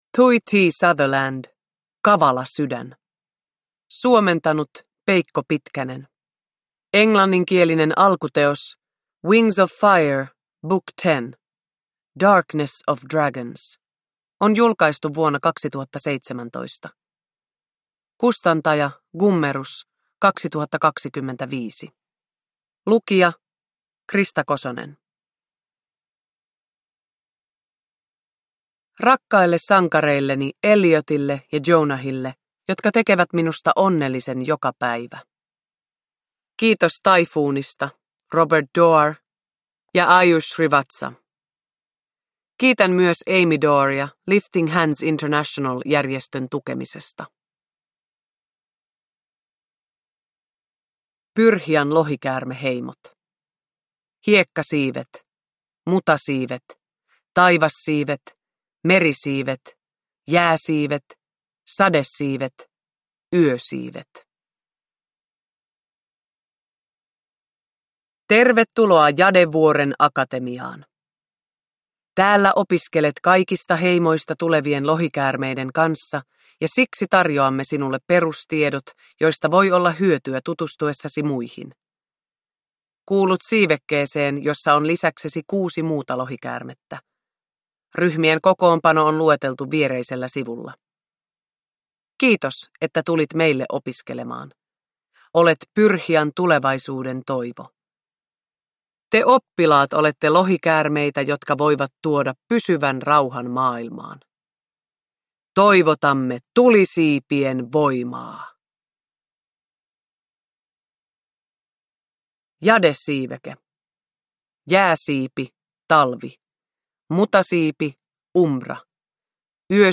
Kavala sydän – Ljudbok
Uppläsare: Krista Kosonen